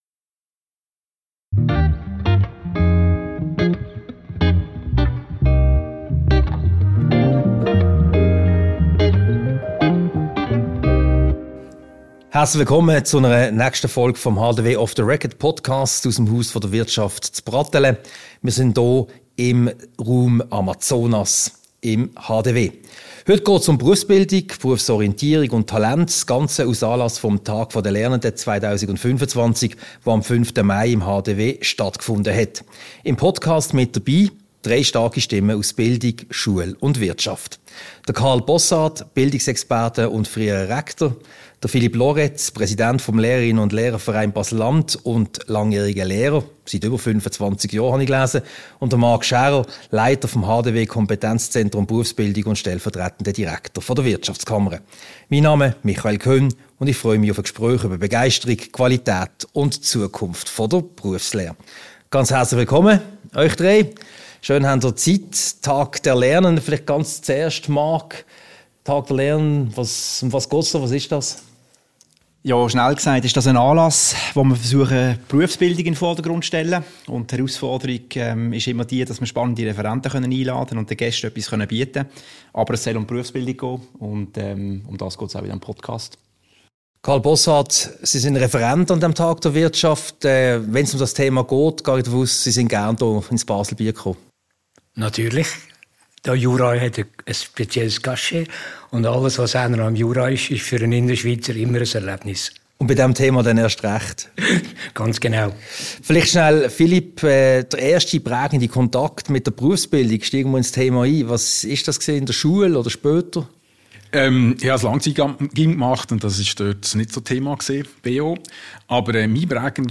Ein Gespräch über Berufsbildung, Berufsorientierung und Talente – und darüber, wie Begeisterung, Qualität und Zukunft in der Berufslehre zusammenfinden. Anlass war der Tag der Lernenden 2025, der am 5. Mai 2025 im Haus der Wirtschaft stattfand.
Diese Podcast-Ausgabe wurde im Multimediastudio der IWF AG im Haus der Wirtschaft HDW aufgezeichnet.